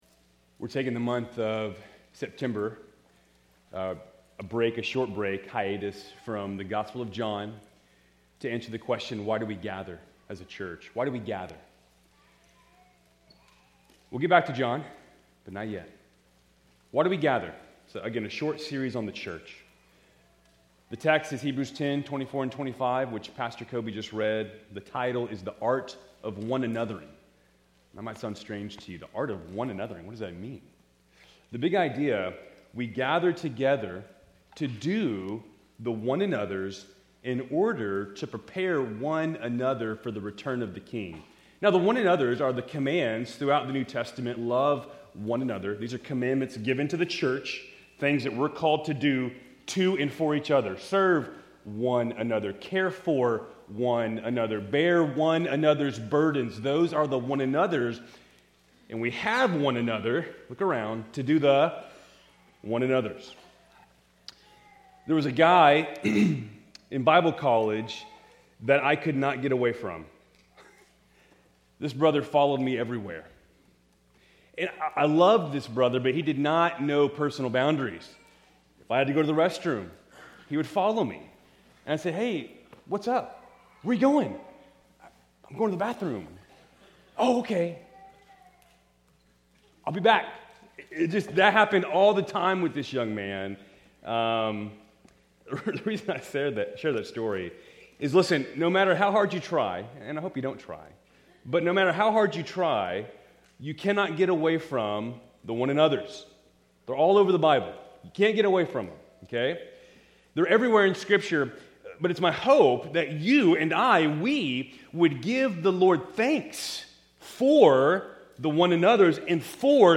Keltys Worship Service, September 14, 2025